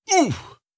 Damage Sounds
18. Damage Grunt (Male).wav